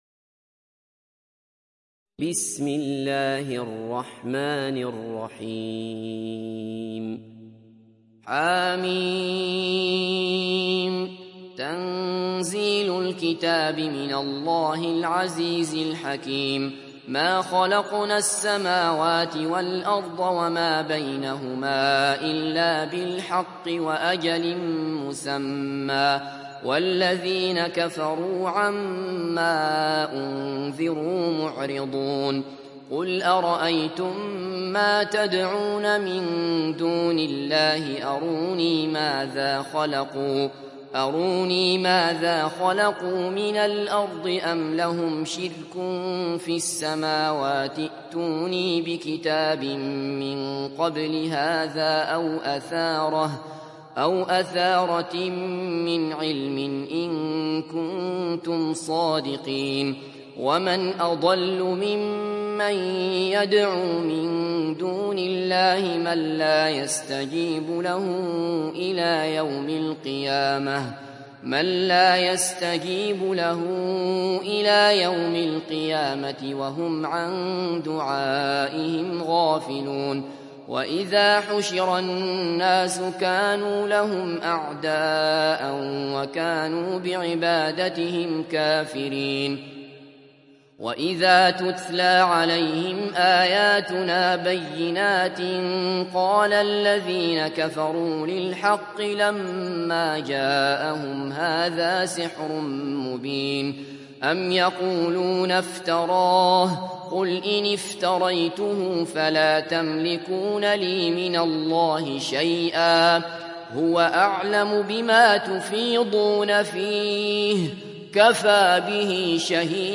تحميل سورة الأحقاف mp3 بصوت عبد الله بصفر برواية حفص عن عاصم, تحميل استماع القرآن الكريم على الجوال mp3 كاملا بروابط مباشرة وسريعة